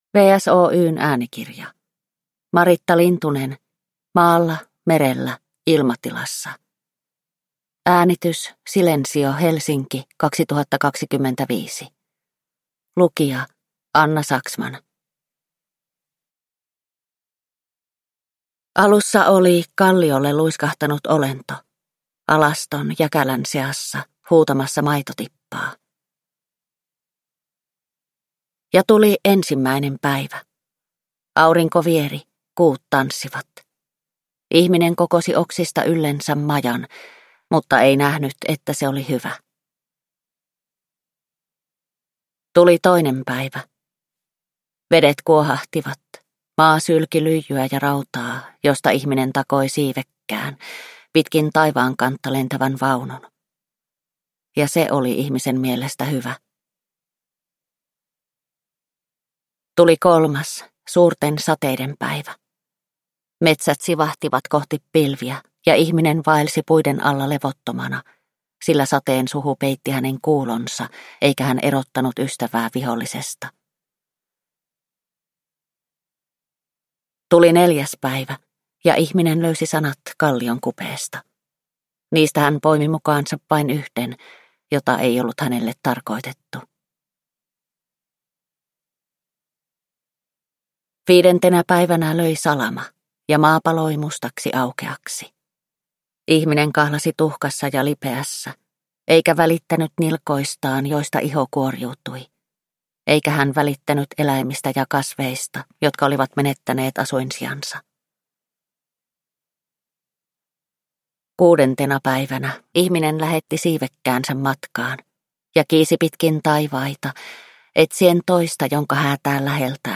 Maalla merellä ilmatilassa – Ljudbok